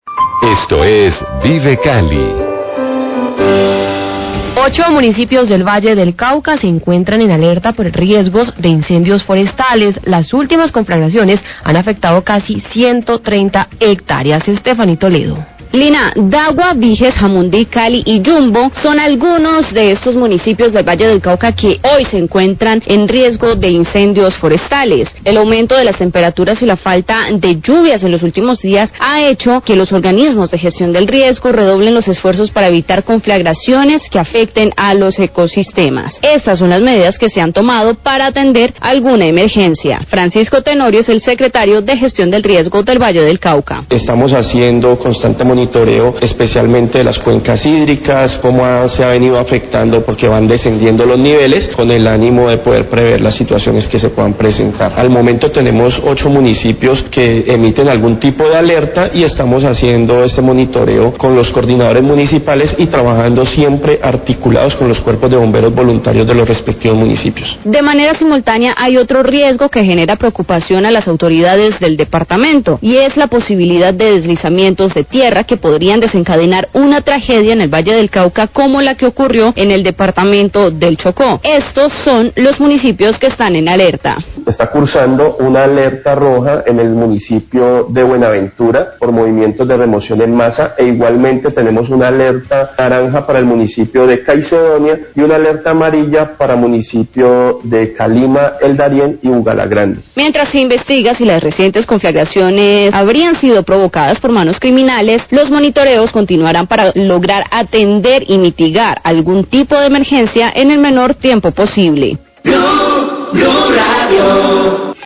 Radio
Francisco Tenorio, Secretario de Gestión del Riesgo de Cali, habla del monitoreo de las cuencas hidrográficas de los rpios en el departamento ante las altas temperaturas y reducción de caudales debido a la ocurrencia del Fenómeno del Niño.